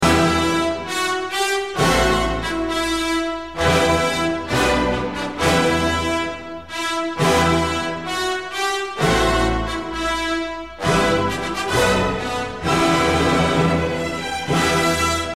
Un petit quizz (auditif) sur la musique classique